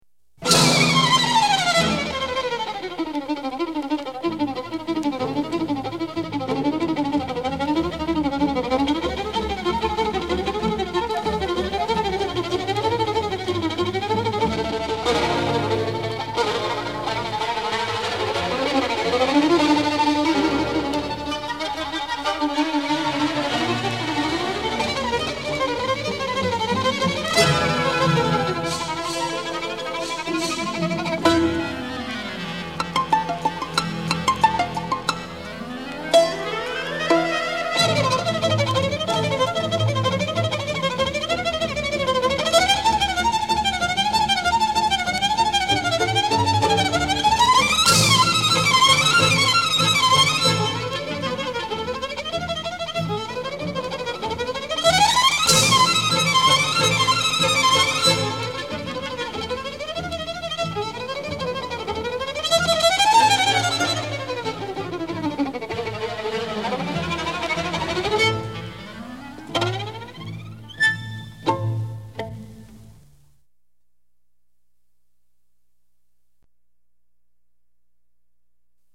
全曲由半音阶的下行乐句开始， 经过旋律轻快而有力的中段后， 又回到了第一主题（片段1）。最后半音阶上升乐句,则描写大黄蜂的离去,直到消失在视线以外。